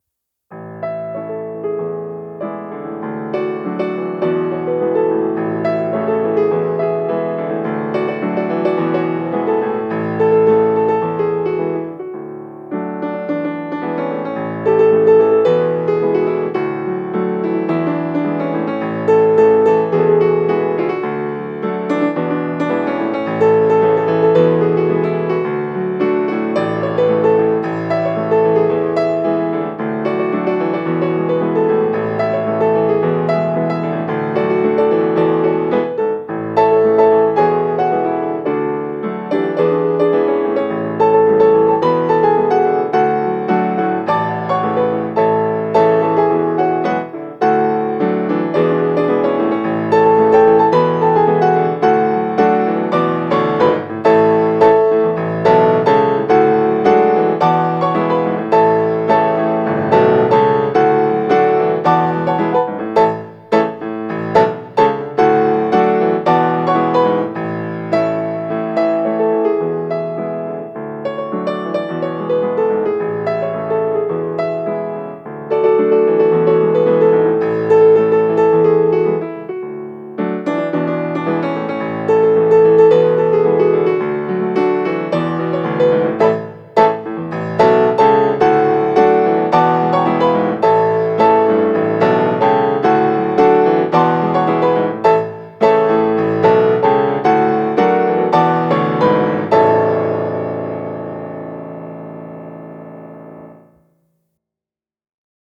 Acoustic Piano Cover